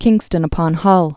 (kĭngstən-ə-pŏn-hŭl, -pôn-)